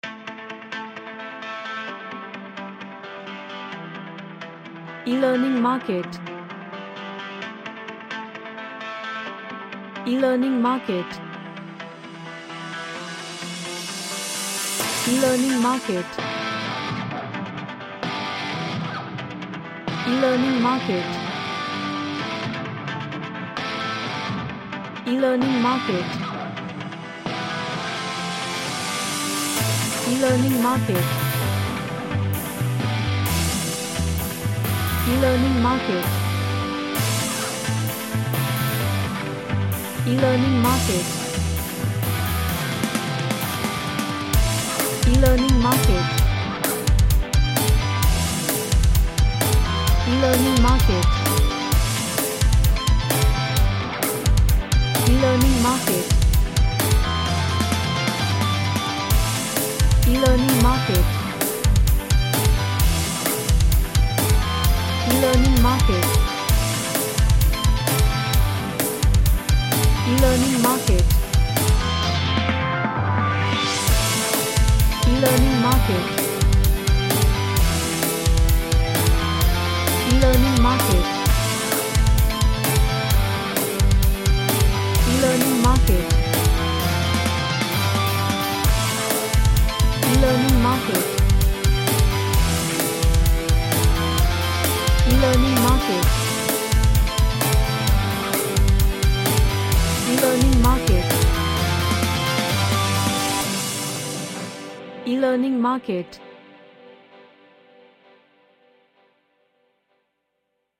An action theme rock music track
Action / Sports